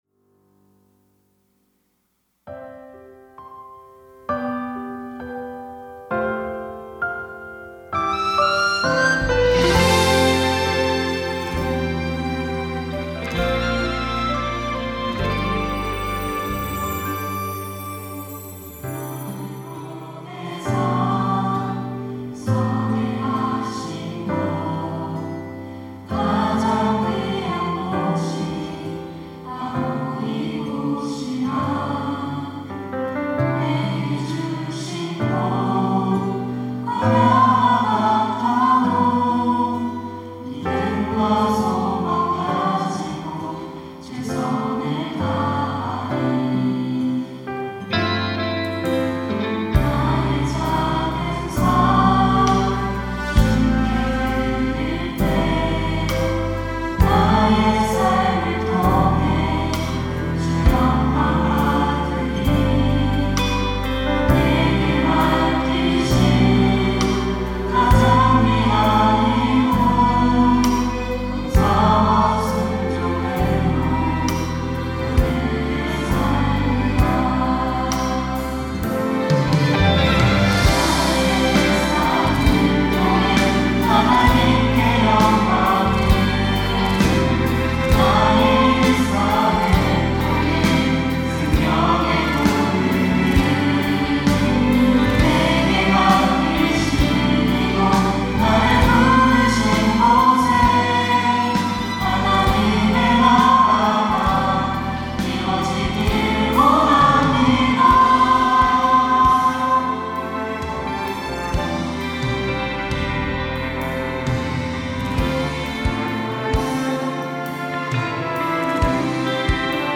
특송과 특주 - 일상
청년부 2025 임원, 교역자